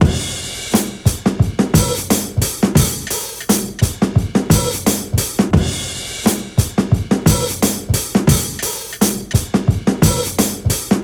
• 87 Bpm Breakbeat Sample D# Key.wav
Free drum beat - kick tuned to the D# note. Loudest frequency: 1083Hz
87-bpm-breakbeat-sample-d-sharp-key-RS7.wav